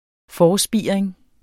Udtale [ ˈfɒː- ]